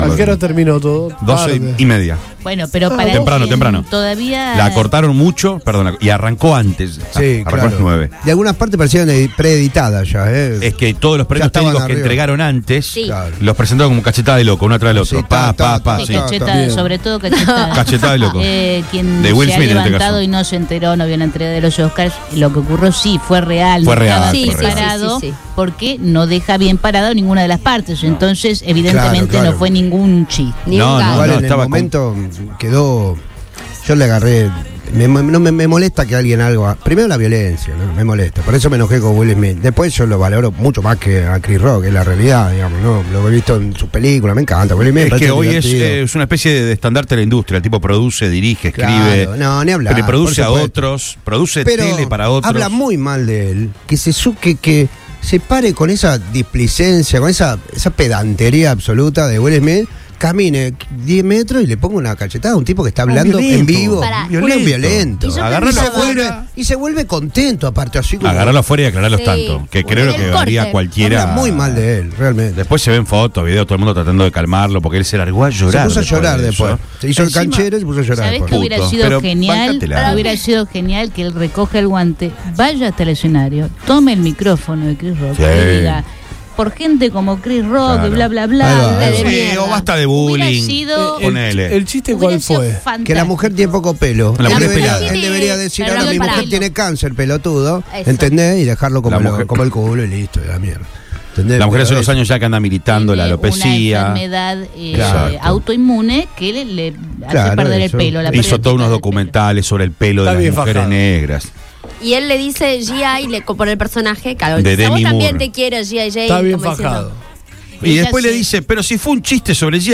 Debate-Will-Smith.mp3